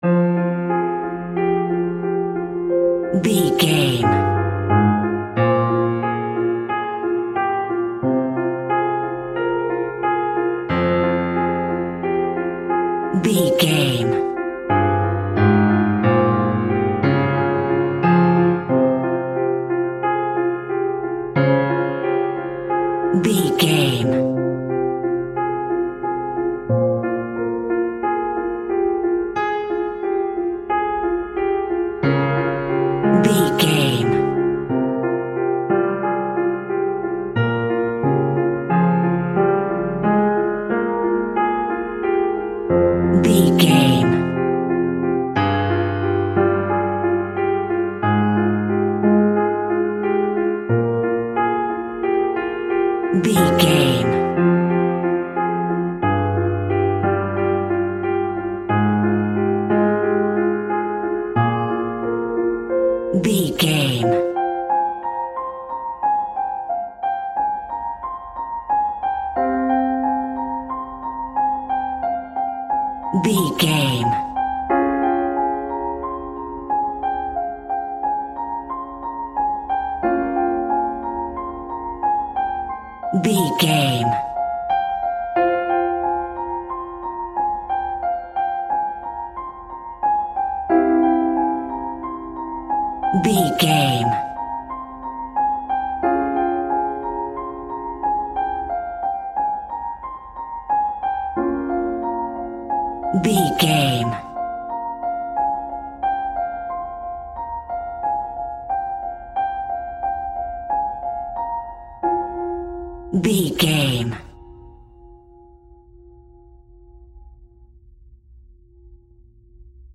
Scary Film Piano Music.
Aeolian/Minor
scary
ominous
haunting
eerie
melancholic
suspenseful